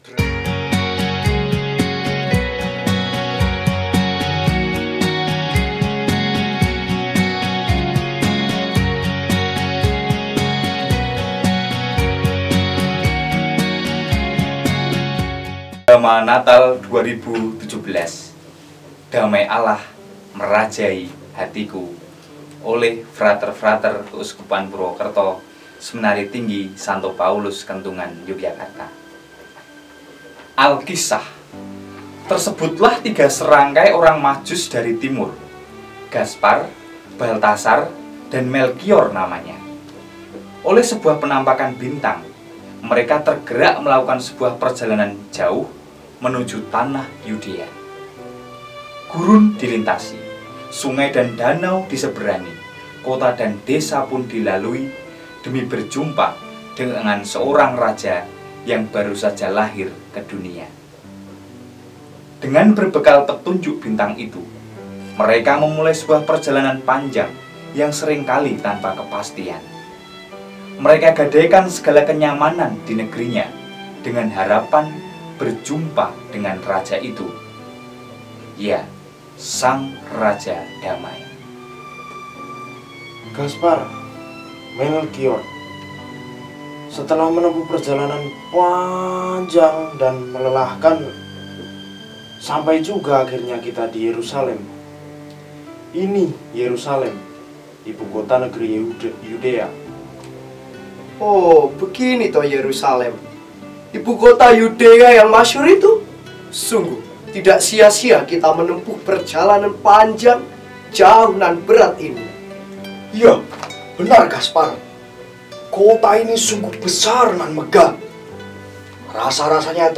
drama Natal singkat para frater edisi khusus